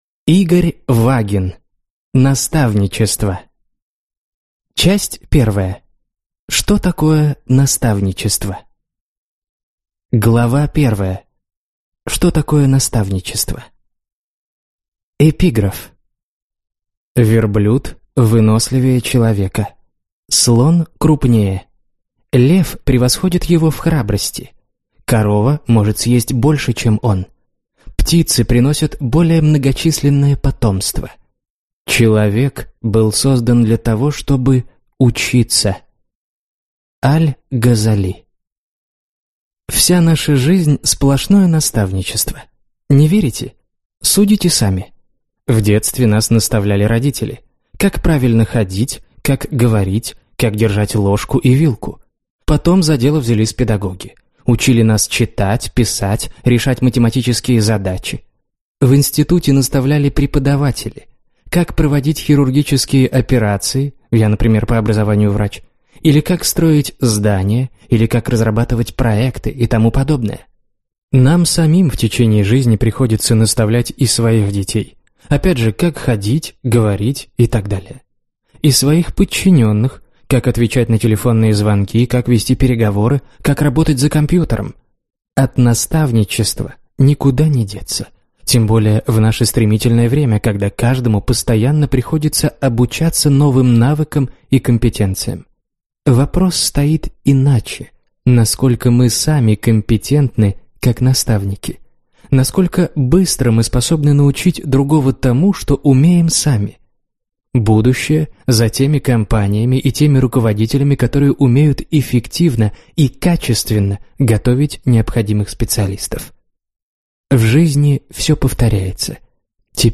Аудиокнига Наставничество | Библиотека аудиокниг
Прослушать и бесплатно скачать фрагмент аудиокниги